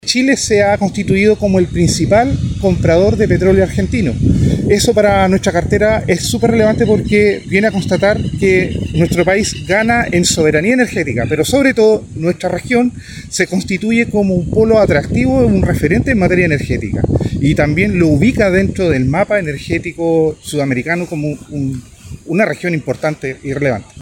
Desde la cartera de Trabajo, la seremi Sandra Quintana valoró el impacto laboral que la reactivación genera para Biobío.